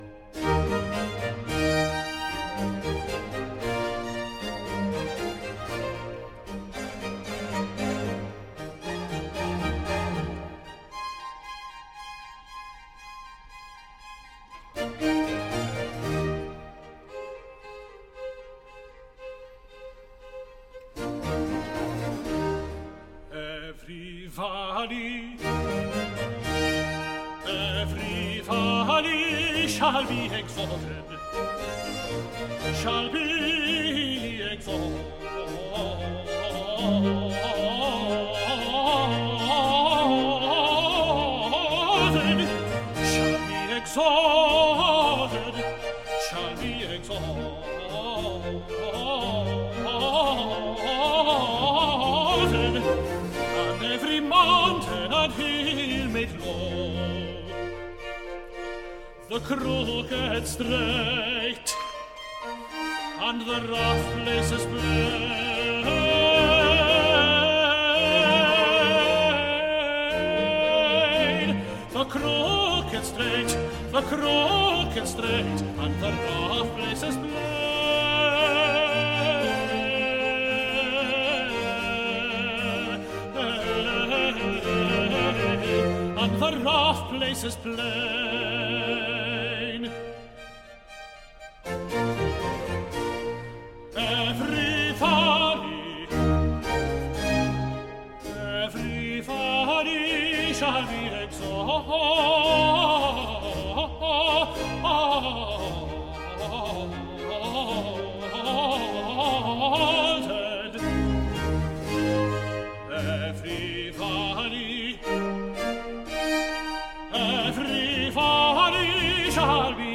Oratorio
Group: Classical vocal
Handel - Messiah - 03 Air _ Every Valley Shall Be Exhalted